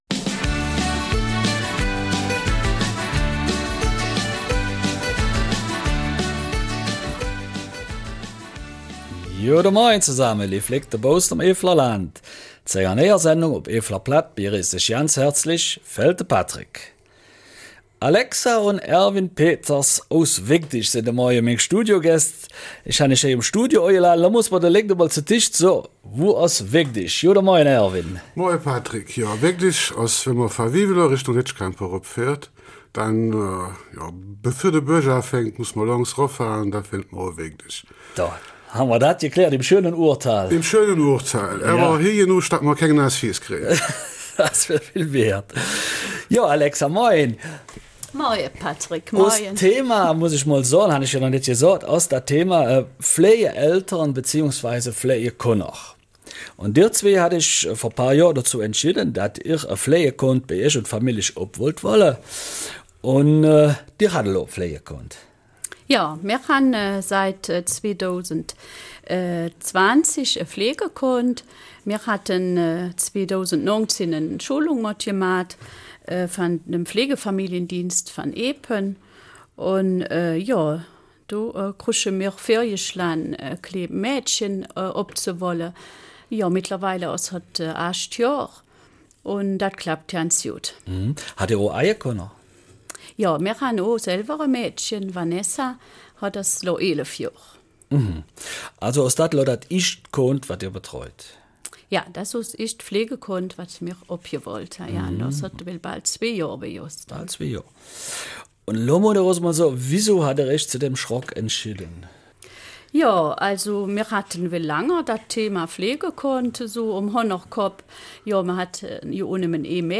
Eifeler Mundart: Pflegefamilie in Weidig